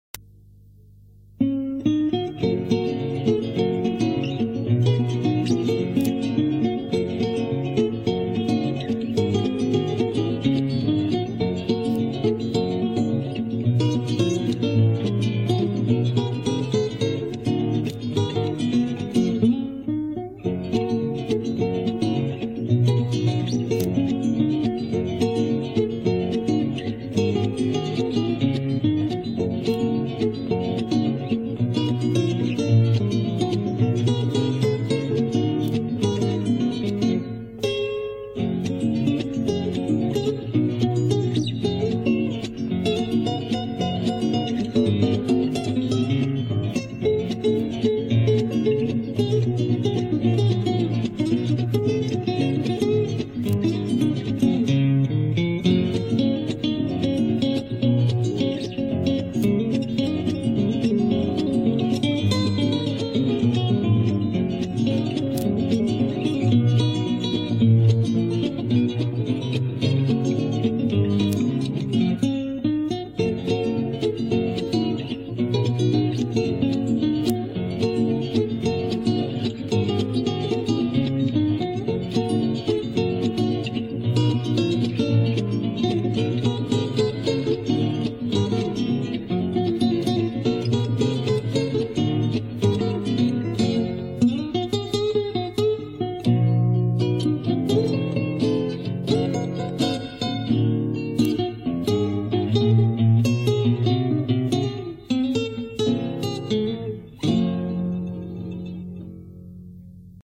Gesang, Gitarre